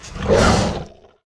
拉抽屉zth070516.wav
通用动作/01人物/06工作生产/拉抽屉zth070516.wav